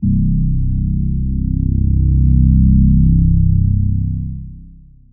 YM - (C) soft reese 808.wav